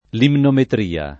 limnometria [ limnometr & a ]